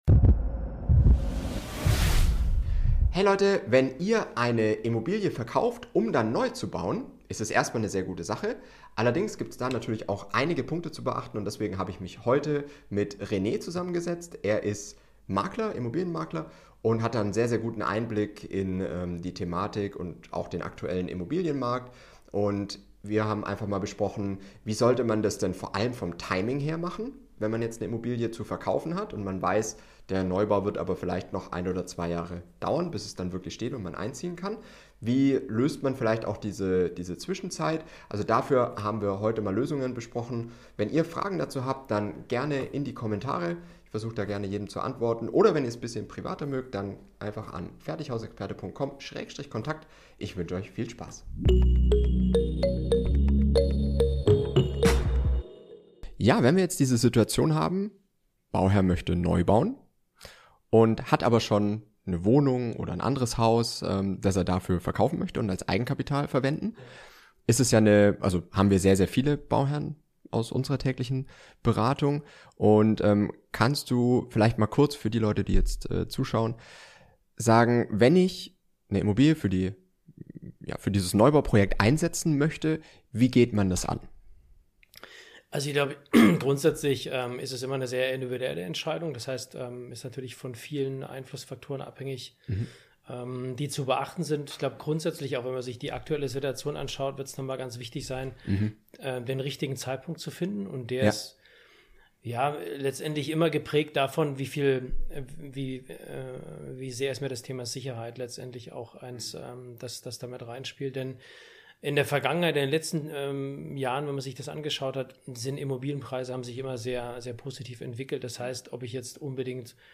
Viel Spaß beim Interview :)